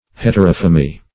Search Result for " heterophemy" : The Collaborative International Dictionary of English v.0.48: Heterophemy \Het`er*oph"e*my\, n. [Hetero- + Gr.